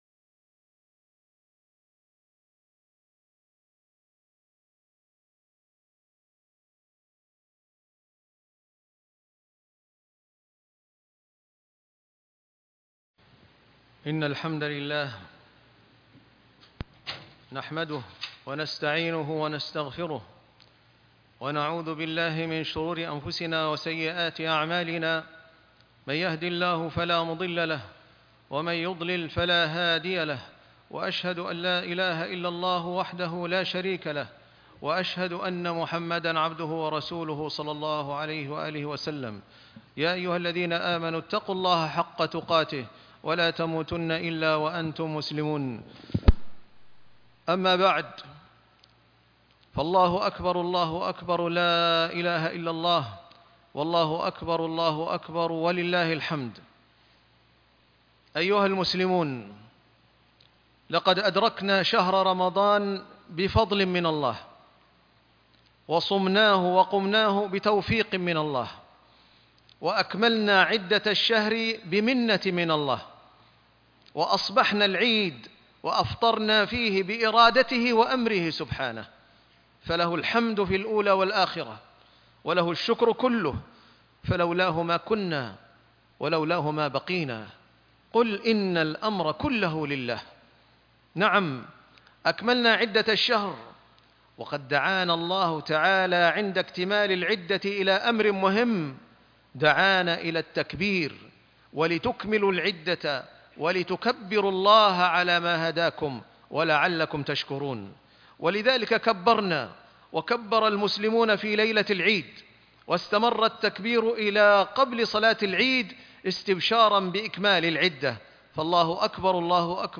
عنوان المادة خطبة العيد الله أكبر